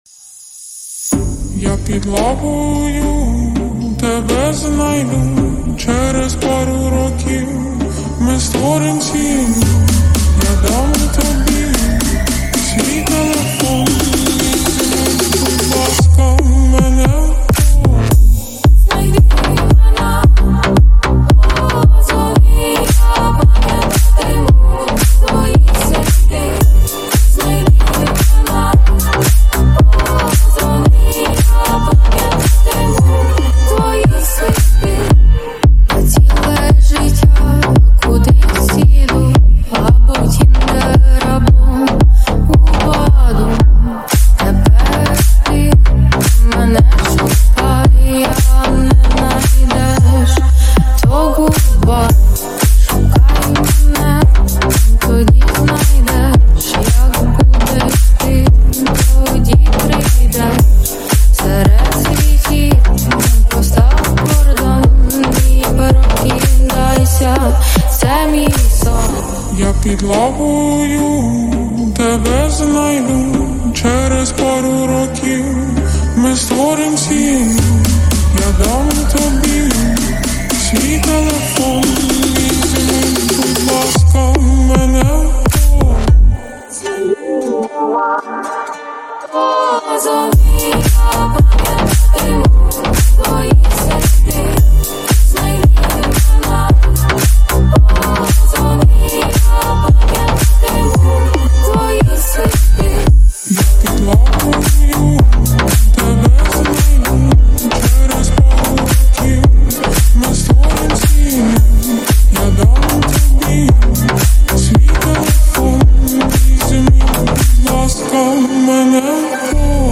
РЕМІКСИ 53:13 128 kbps 48.72 Mb